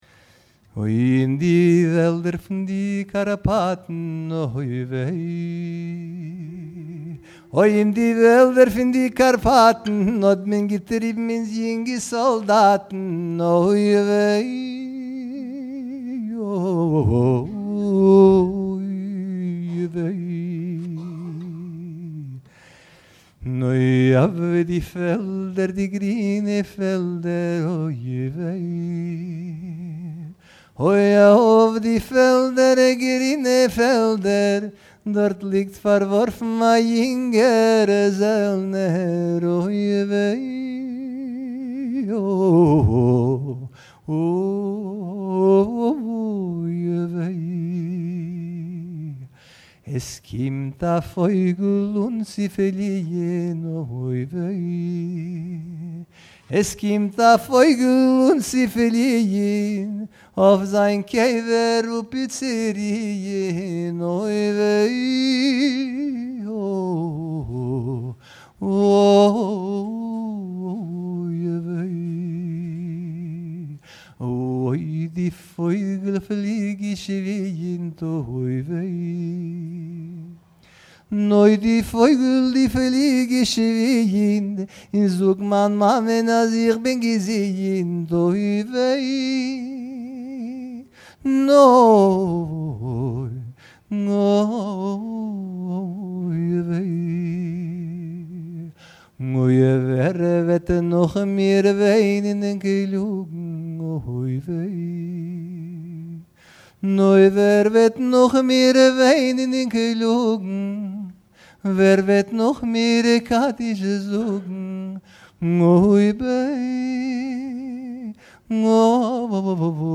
In di velder fun di Karpatn (In the Forests of the Carpathians), a song of Jewish soldiers on the Eastern Front in World War I. Words and melody: Michael Alpert, based on traditional versions. Recorded August 2003, Center for Jewish History, New York NY.